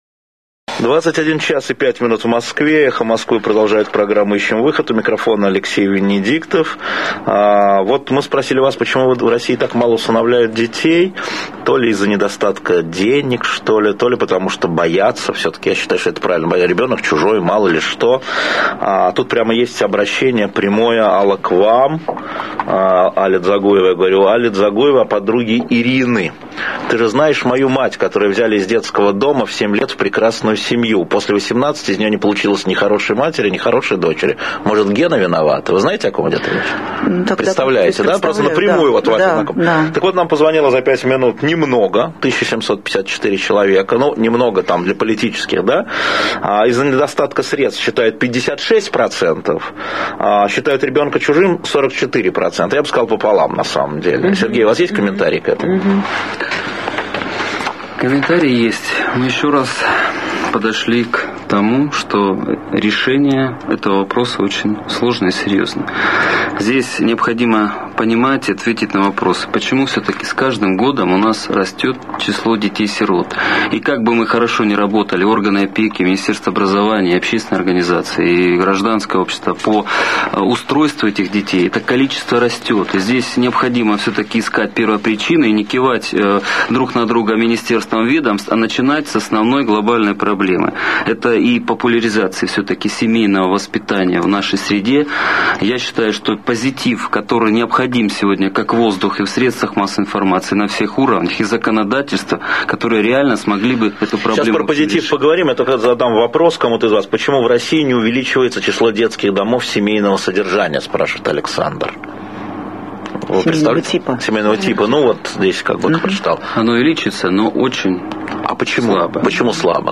Интервью Светланы Сорокиной на Эхе Москвы